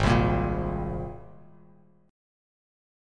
G#add9 chord